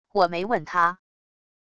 我沒问她wav音频生成系统WAV Audio Player